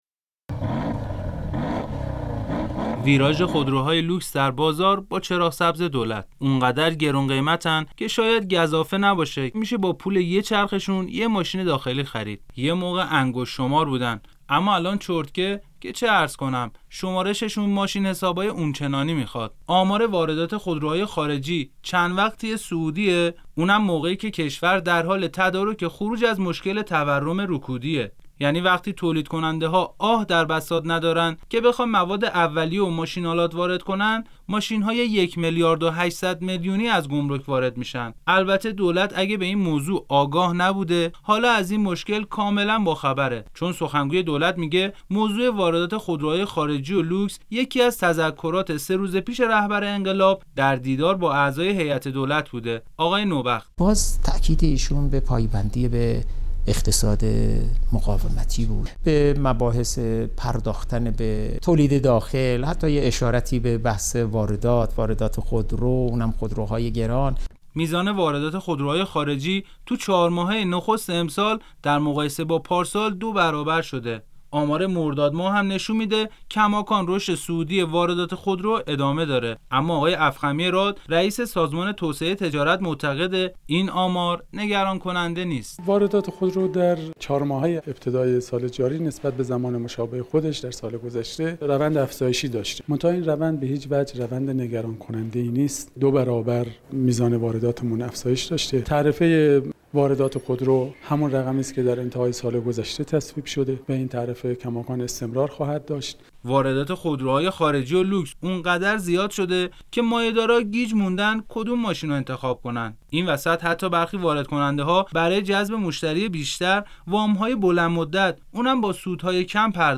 گزارش "شنیدنی" از افزایش واردات خودروهای لوکس - تسنیم